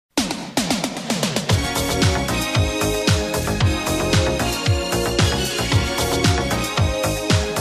sbornik smeshnykh pukov i sound effects
sbornik-smeshnykh-pukov-i